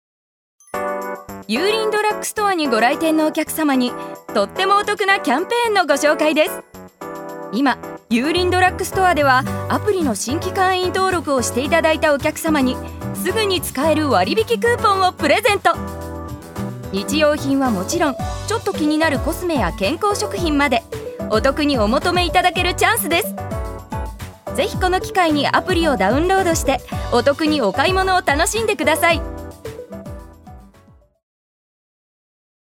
ジュニア：女性
ナレーション２